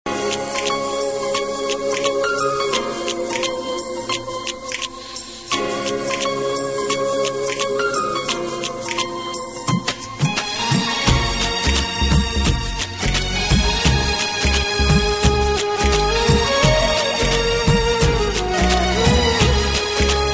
Download Flute Ringtone